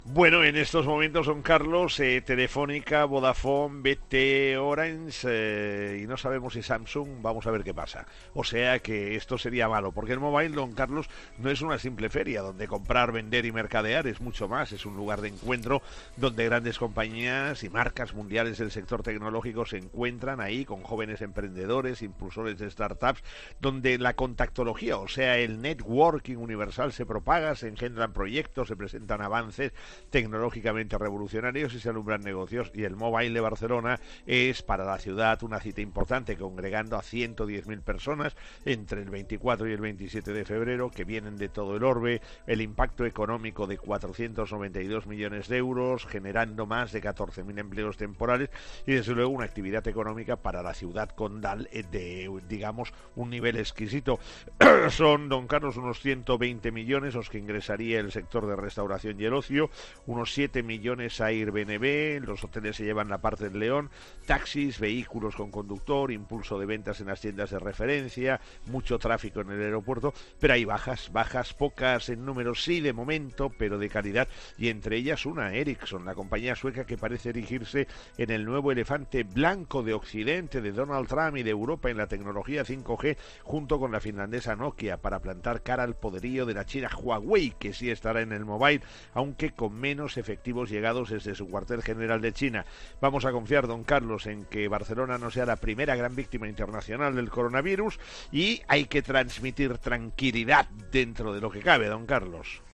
El profesor José María Gay de Liébana explica el impacto económico del Mobile en 'Herrera en COPE'